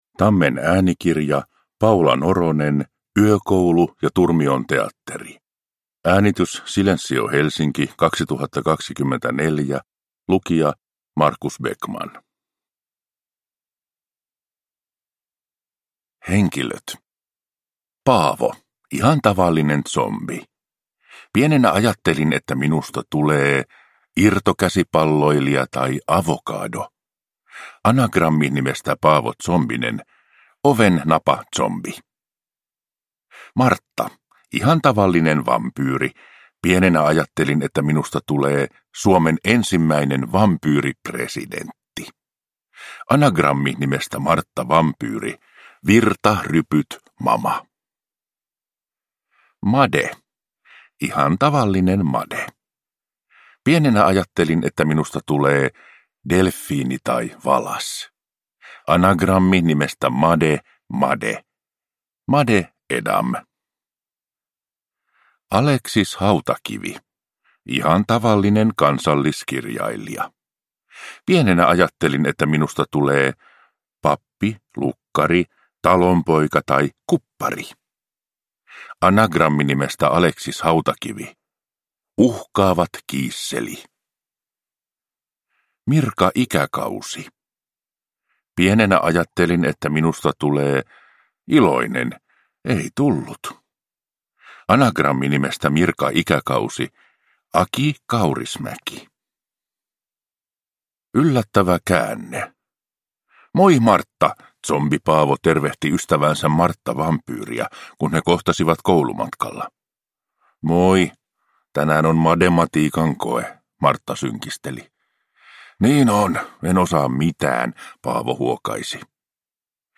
Yökoulu ja turmion teatteri – Ljudbok